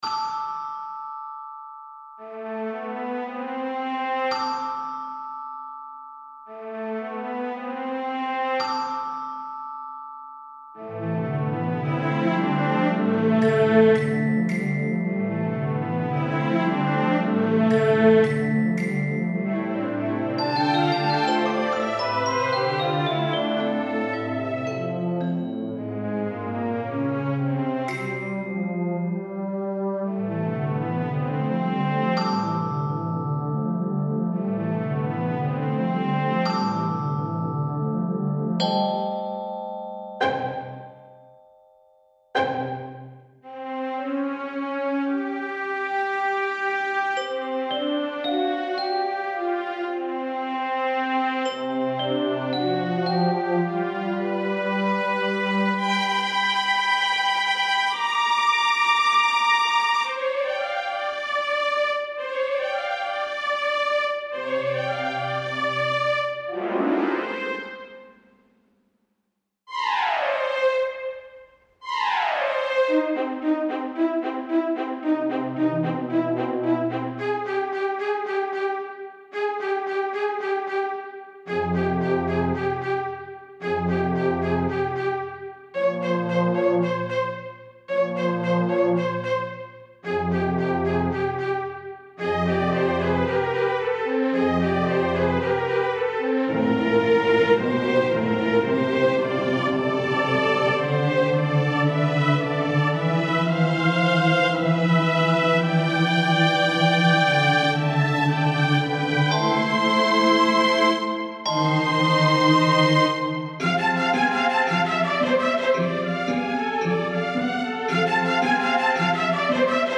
A new work of contemporary classical music.
New version arranged for string quartet and celeste.